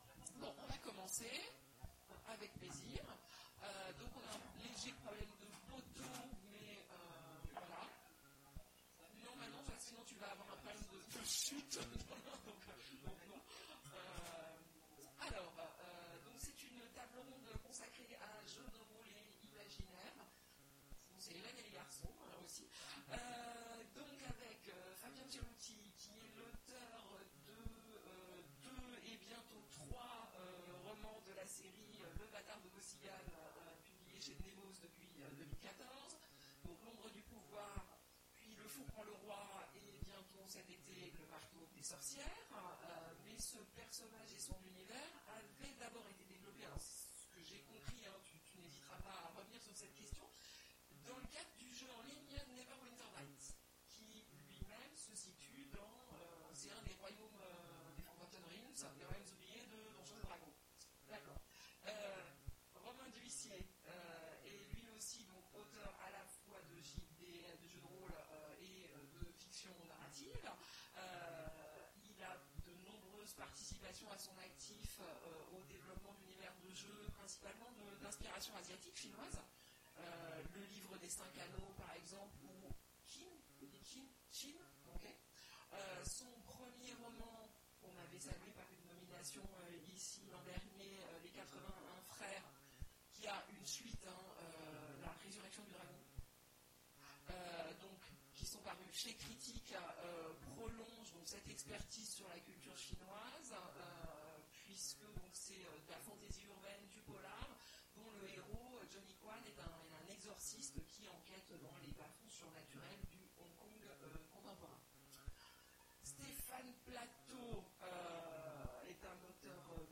Mots-clés Jeu de rôle Conférence Partager cet article